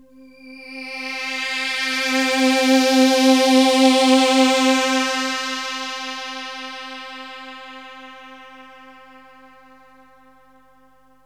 AMBIENT ATMOSPHERES-1 0004.wav